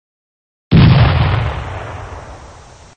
Bomb1.mp3